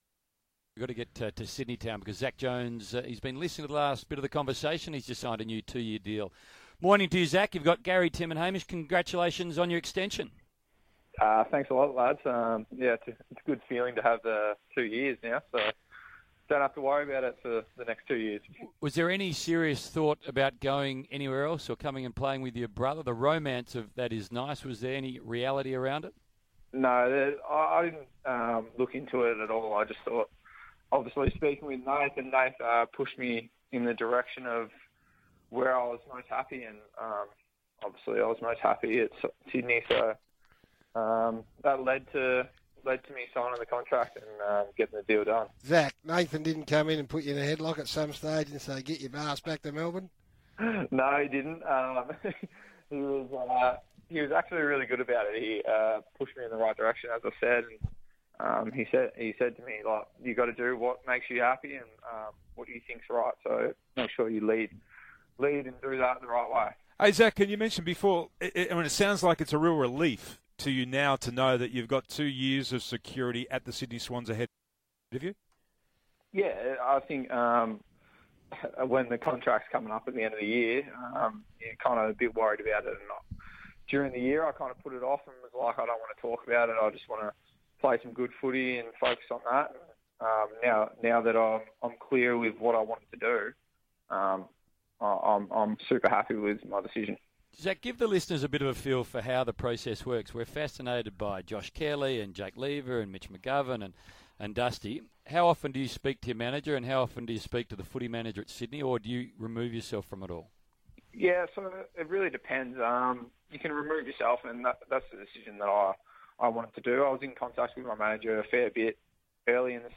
Newly re-signed midfielder Zak Jones chats to SEN Breakfast's Garry Lyon, Hamish McLachlan and Tim Watson.